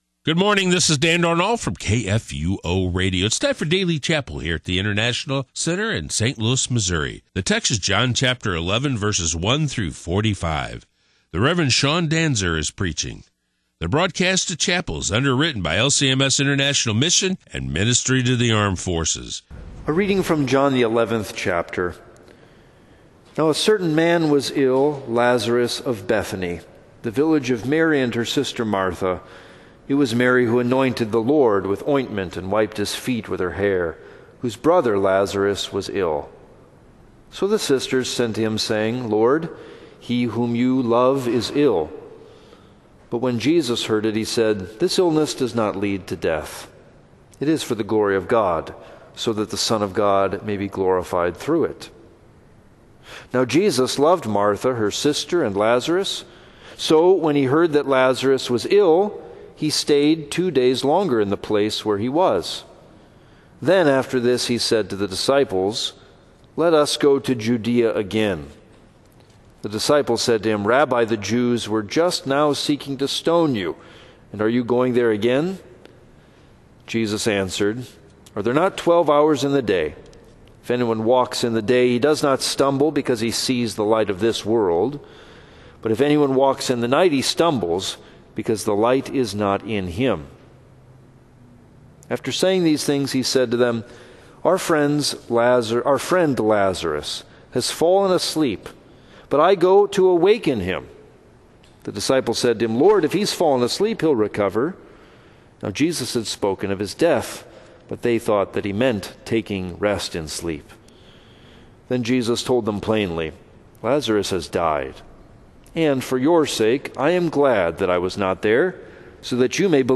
>> The broadcast of chapel services is brought to you by LCMS International Mission and Ministry to Armed Forces.